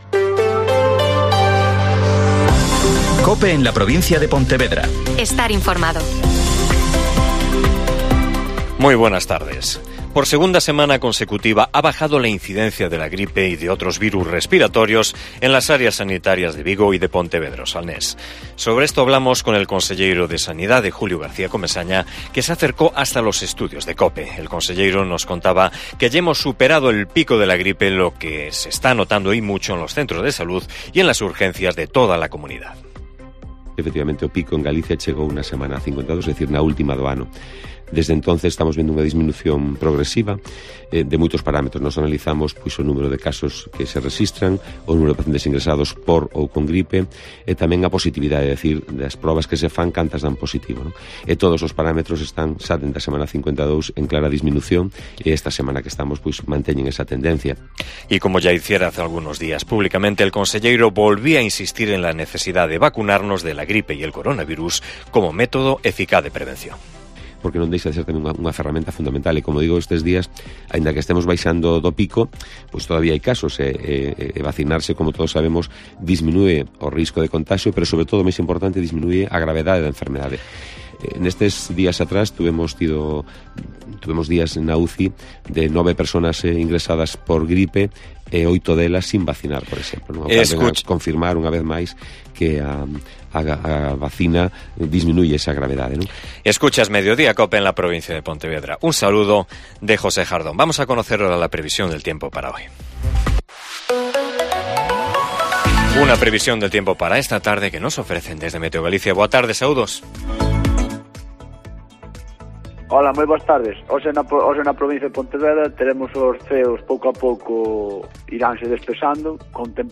AUDIO: Mediodía COPE en la Provincia de Pontevedra (Informativo 14:20h)